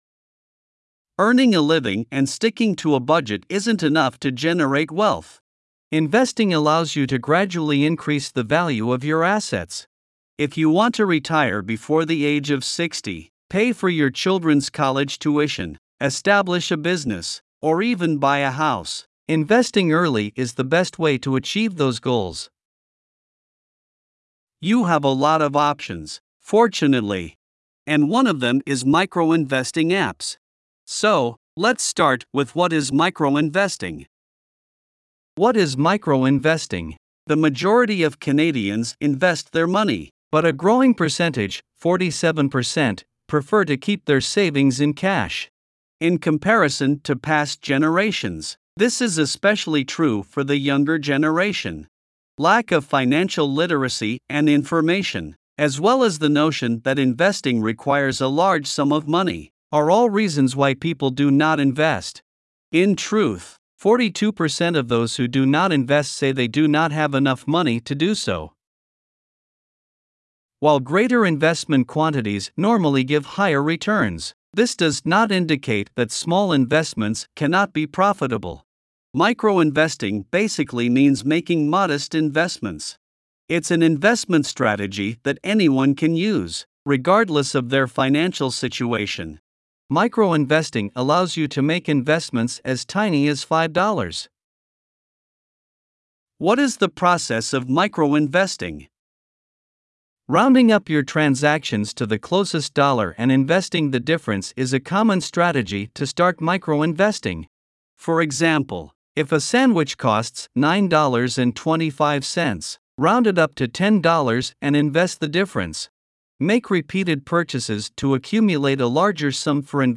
Voiceovers-Voices-by-Listnr_6.mp3